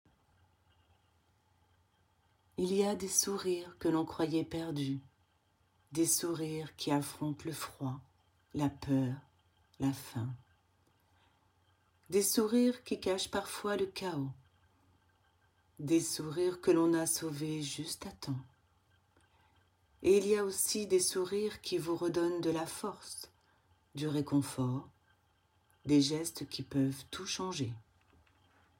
Voix off
Texte pub